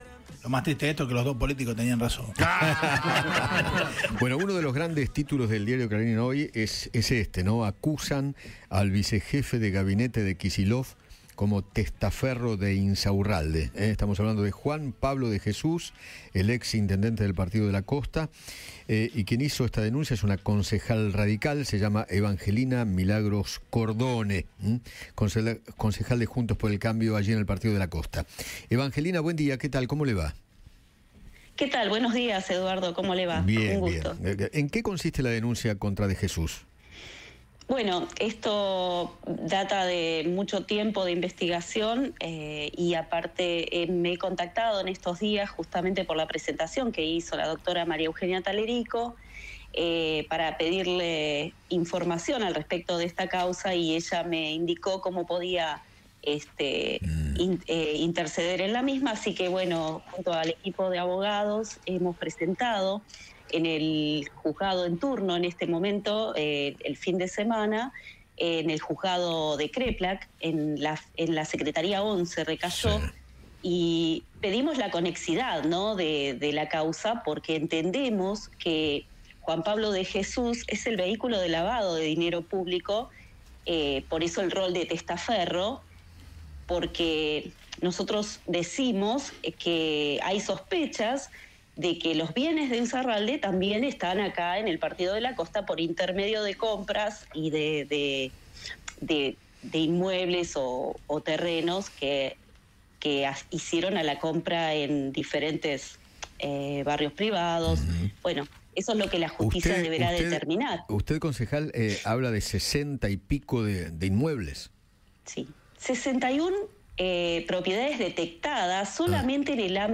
Evangelina Cordone, concejal del Partido de la Costa por Juntos por el Cambio, habló con Eduardo Feinmann sobre la denuncia que realizó contra el vicejefe de gabinete de Axel Kicillof.